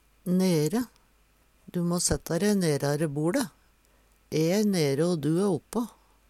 nere - Numedalsmål (en-US)
DIALEKTORD PÅ NORMERT NORSK nere nær, nede Eksempel på bruk Du må setta de nerare boLe.